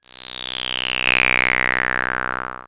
ihob/Assets/Extensions/RetroGamesSoundFX/Hum/Hum29.wav at master
Hum29.wav